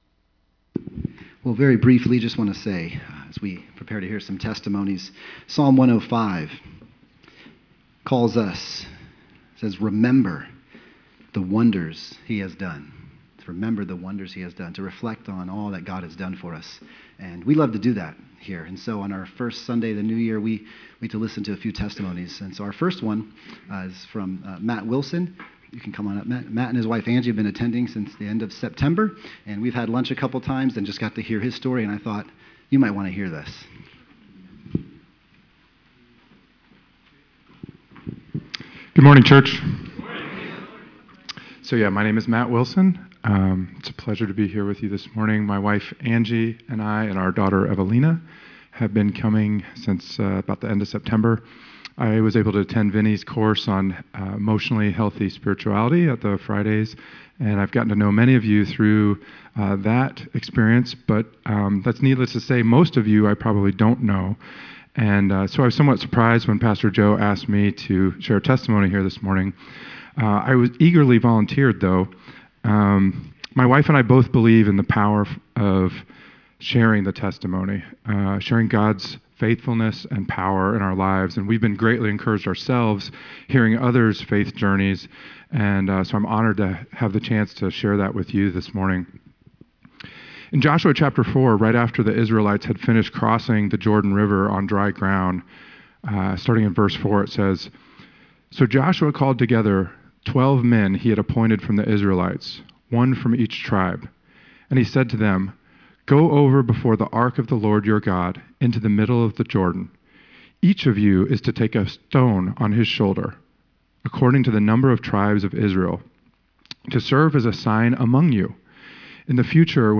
First Sunday Testimonies